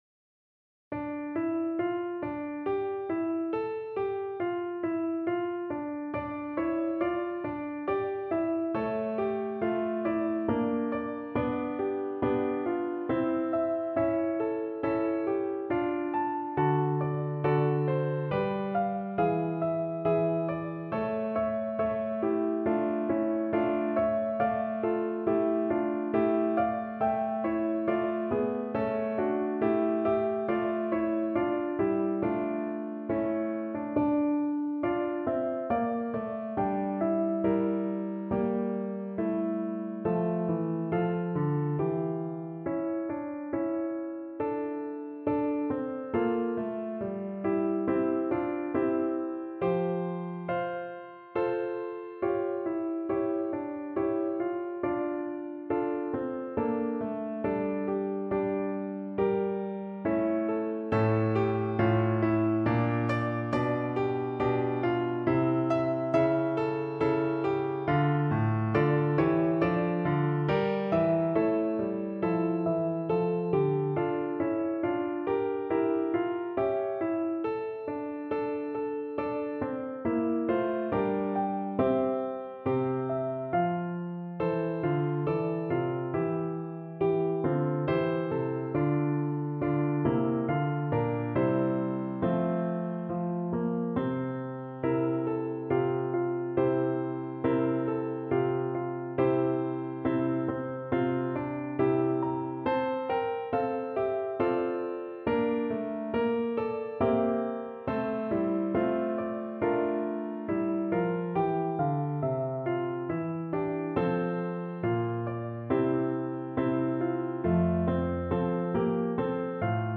= 69 Largo
3/4 (View more 3/4 Music)
Classical (View more Classical Flute Music)